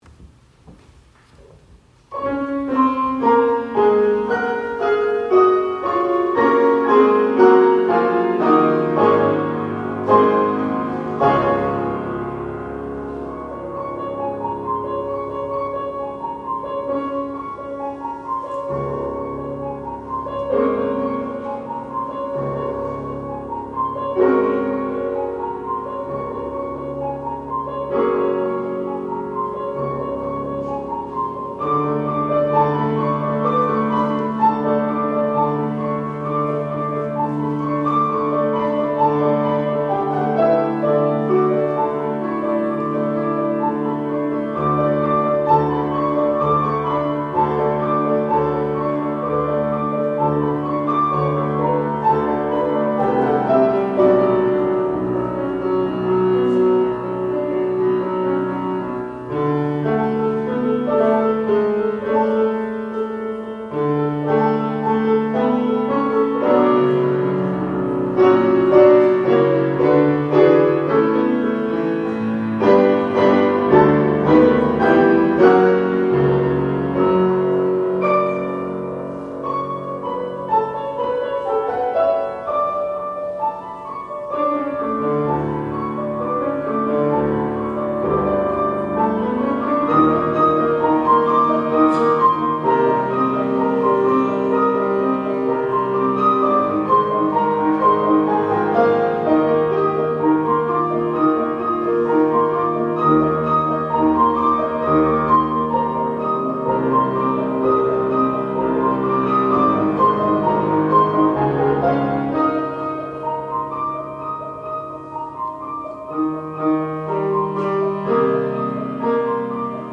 Offertory